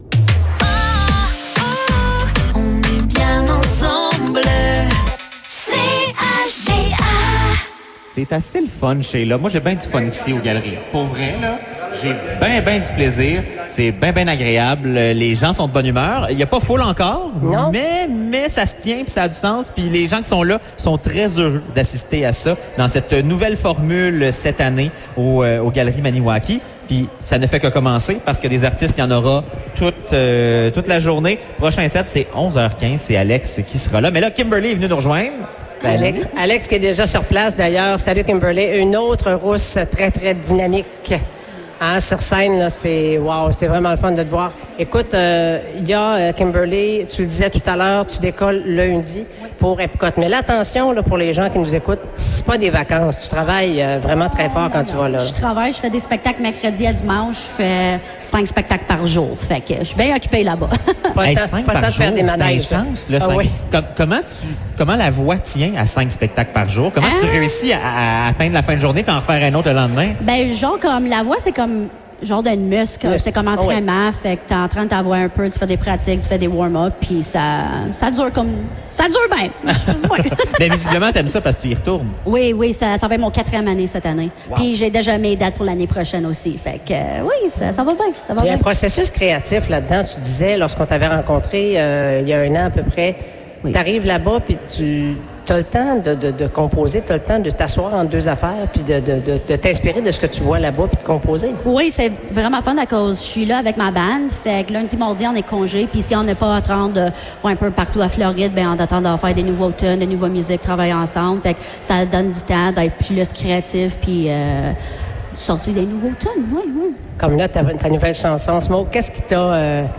Épisode Radiothon 2026 - Entrevue